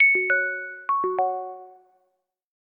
Ding_Dong.ogg